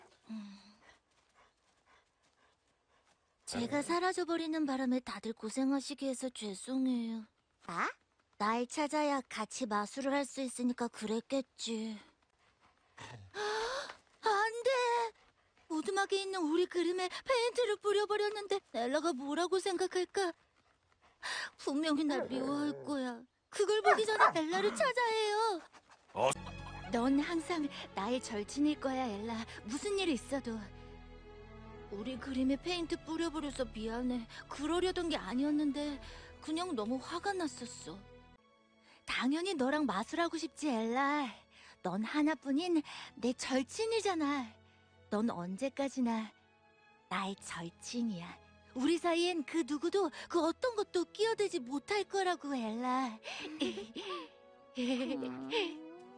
성우샘플